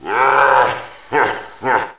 laugh3_com.wav